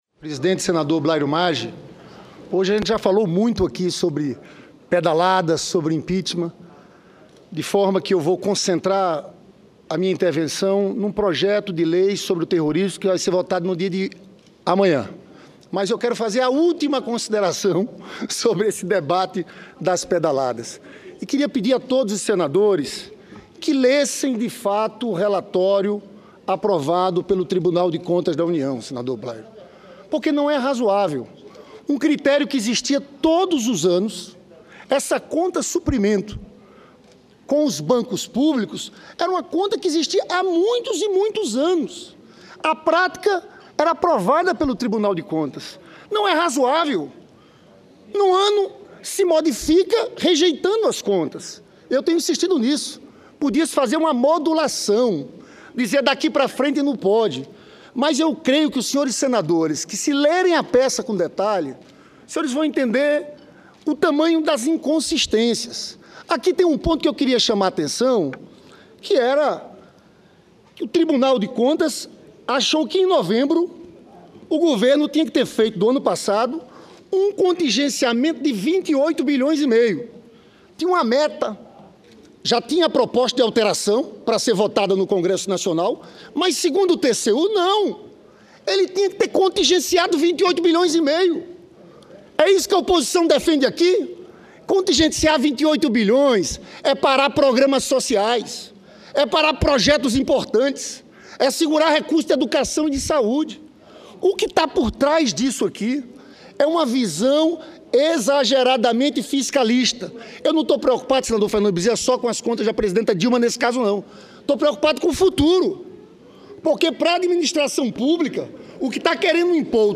Discursos